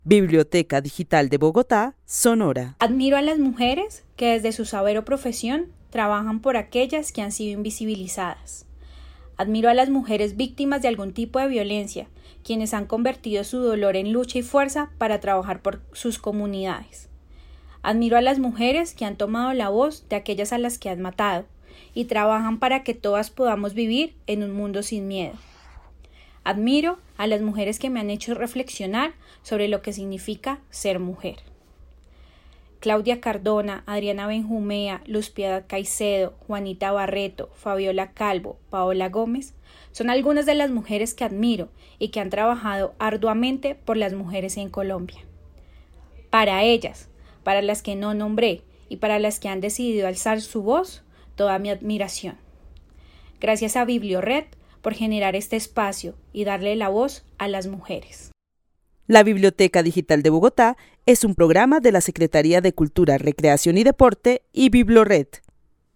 Narración oral de una mujer que vive en la ciudad de Bogotá quien admira a las mujeres que desde su profesión o experiencia, trabajan por las que han sido invisibilizadas. Admira a las mujeres que han sido víctimas de algún tipo de violencia y transforman su dolor en fuerza para trabajar por sus comunidades, también admira a las mujeres que han tomado la voz de aquellas a las que han matado y trabajan por un mundo sin miedo. El testimonio fue recolectado en el marco del laboratorio de co-creación "Postales sonoras: mujeres escuchando mujeres" de la línea Cultura Digital e Innovación de la Red Distrital de Bibliotecas Públicas de Bogotá - BibloRed.